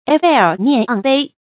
晬面盎背 注音： ㄗㄨㄟˋ ㄇㄧㄢˋ ㄤˋ ㄅㄟˋ 讀音讀法： 意思解釋： 謂德性表現于外，而有溫潤之貌，敦厚之態。